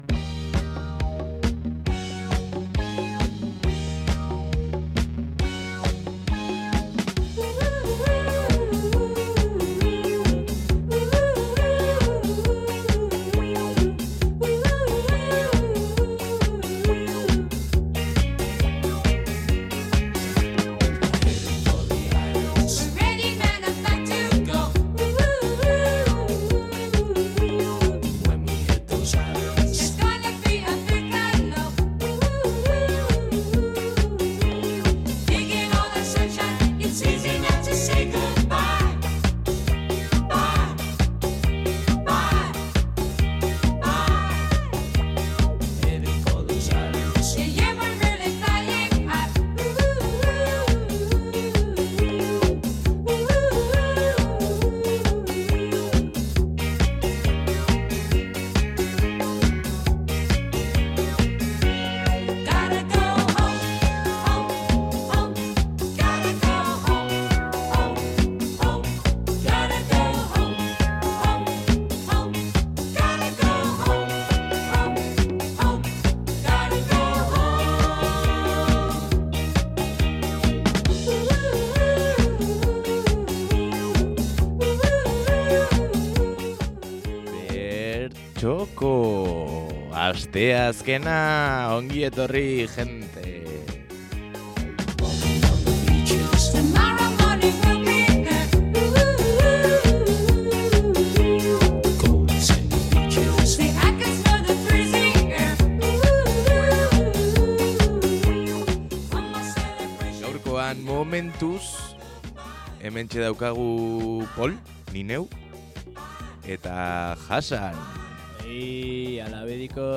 Aquí podéis escuchar la entrevista completa: